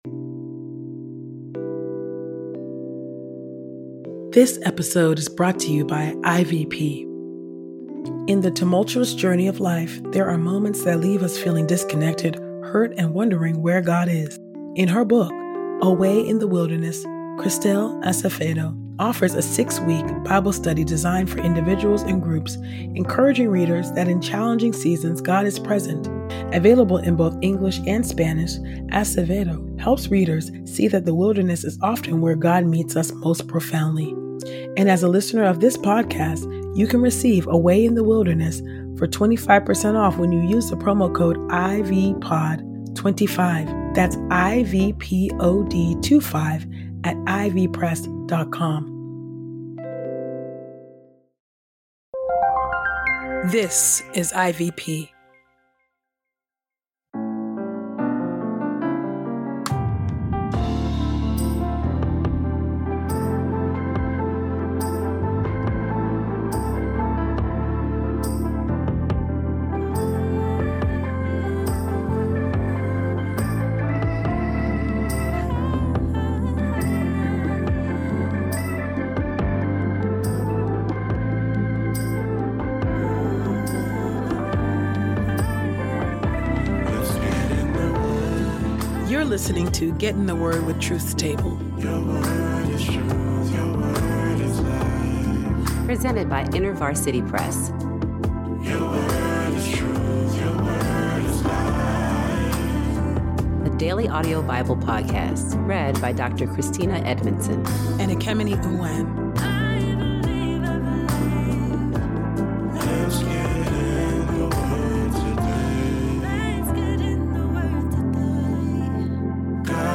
a daily Bible podcast narrated